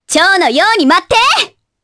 Reina-Vox_Skill1_jp.wav